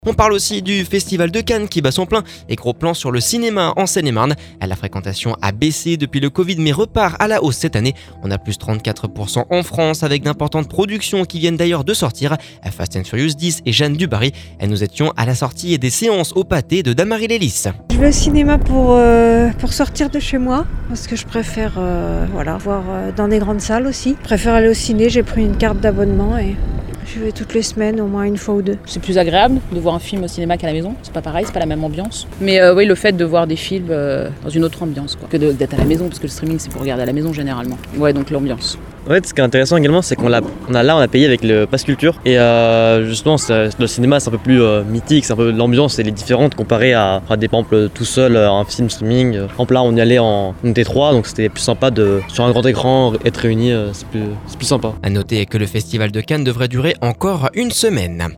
Nous étions à la sortie des séances au cinéma Pathé de Dammarie Les Lys…